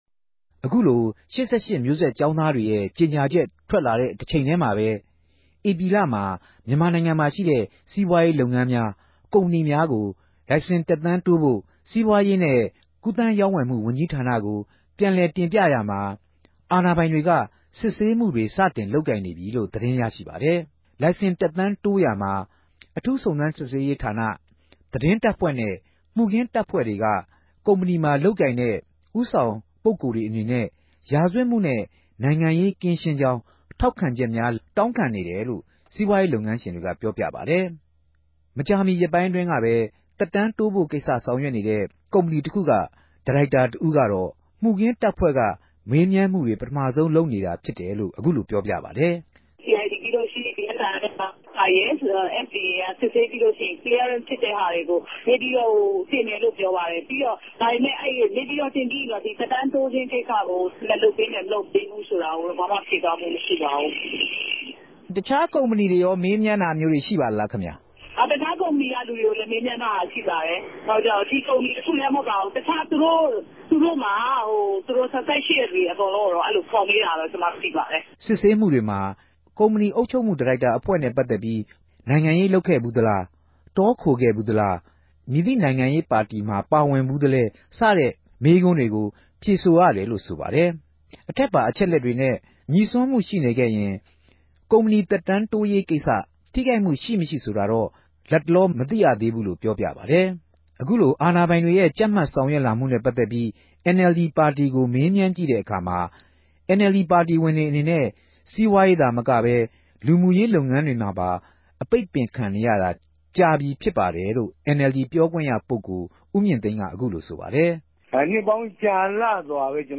ဒီကနေႛထုတ်ူပန်တဲ့ ၈၈မဵိြးဆက် ကေဵာင်းသားတေရြဲ့ ေုကညာခဵက်မြာ ူမန်မာိံိုင်ငံသားတိုင်းဟာ စီးပြားရေး၊ လူမြရေးမြန်သမ္တွမြာ ိံိုင်ငံရေး ယုံုကည်ခဵက် ဘယ်လိုပဲ ရြိပၝစေ တူညီတဲ့ အခြင့်အရေးနဲႛ ရပိုင်ခြင့်တြေ ရြိရမြာူဖစ်လိုႛ တပ်မတော်အစိုးရမြ ူဖည့်ဆည်း ဆောင်႟ြက်ပေးသင့်တယ်လိုႛ တိုက်တြန်းထားတာကို ၈၈မဵိြးဆက် ကေဵာင်းသားတဦးက RFAကို ဖတ်ူပပၝတယ်။